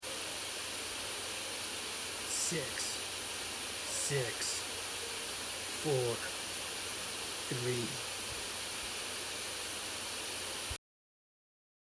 If the players listen carefully, in the static they can hear the numbers six-six-four-three, which form the combination to the box’s lock.